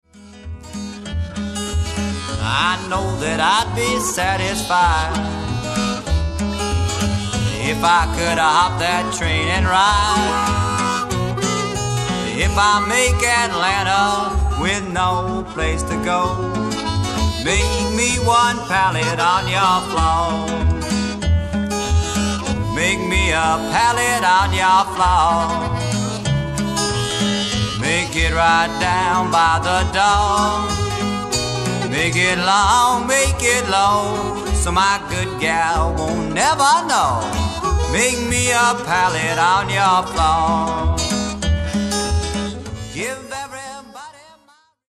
60年代の「コーヒーハウス」文化を匂わす温もりのある録音が聴き所。
ハーモニカ